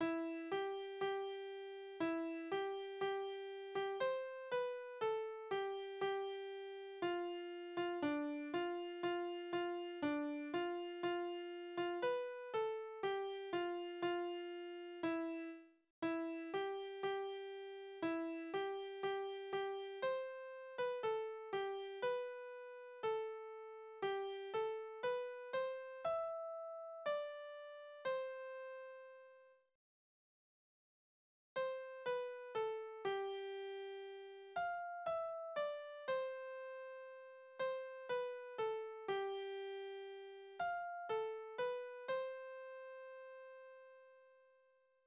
Kindertänze: Hier ist grün
Tonart: C-Dur
Taktart: 4/4
Tonumfang: kleine Dezime